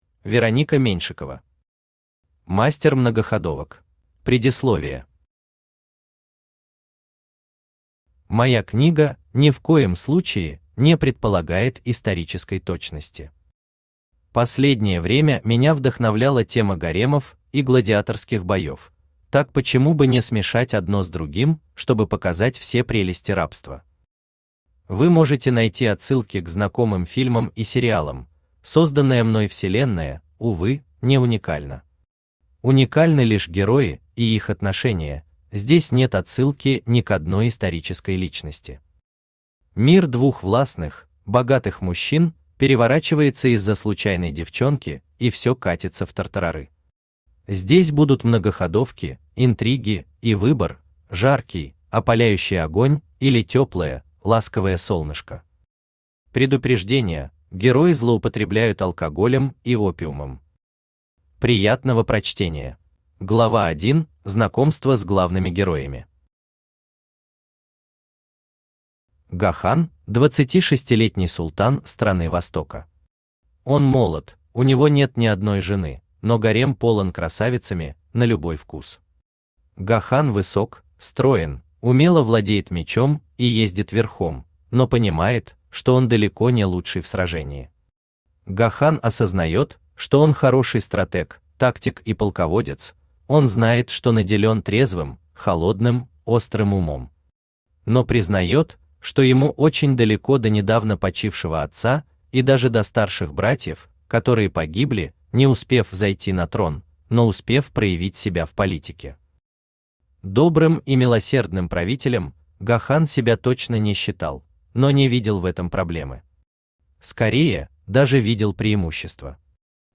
Аудиокнига Мастер многоходовок | Библиотека аудиокниг
Aудиокнига Мастер многоходовок Автор Вероника Сергеевна Меньшикова Читает аудиокнигу Авточтец ЛитРес.